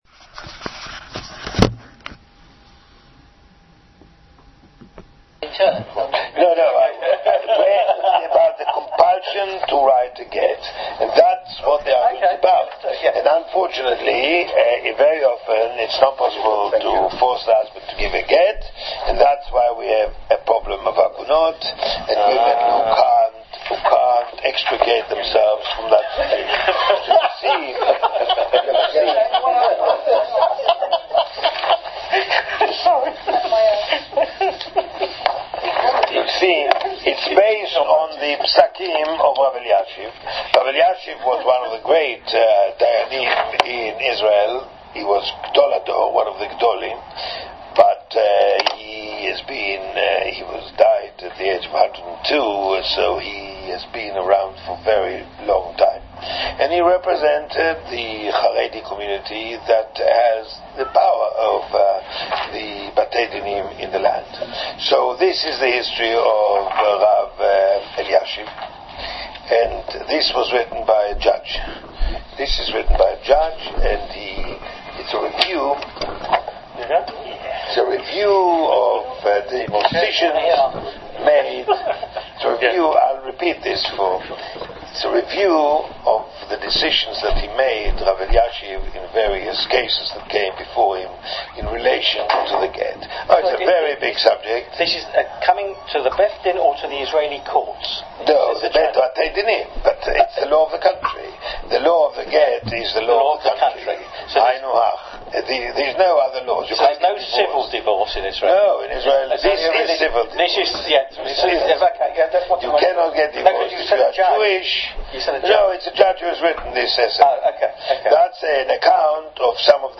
In this shiur we discuss fascinating cases which appeared before Rav Elyashiv ZT’L regarding Forced Gets.